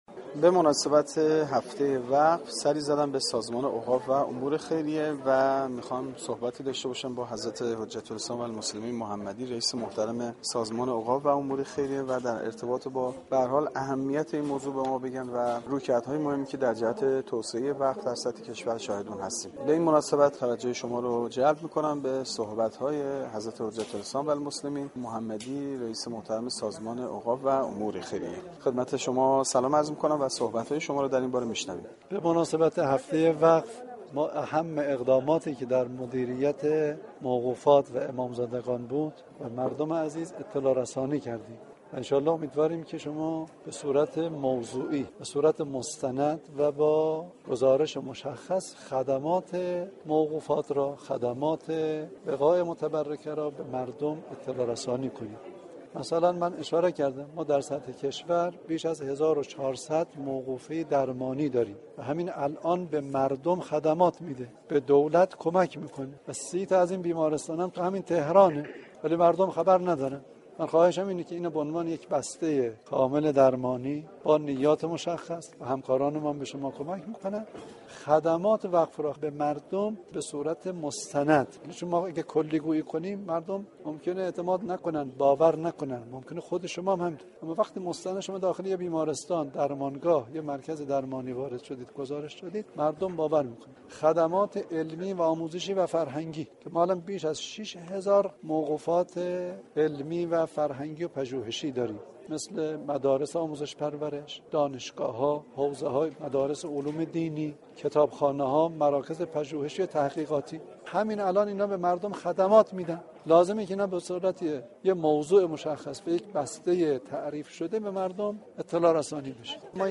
حجت الاسلام والمسلمین محمدی رییس سازمان اوقاف و امور خیریه به بهانه ی آغاز هفته ی وقف در گفتگو با گزارشگر رادیو فرهنگ درباره ی اهمیت موضوع وقف و رویكردهایی كه در جهت توسعه ی وقف در سراسر كشور انجام شده است گفت : بیش از 1400 موقوفه ی درمانی در سطح كشور در حال ارائه خدمات به مردم هستند كه تعداد سی تا از این مراكز در شهر تهران واقع شده اند .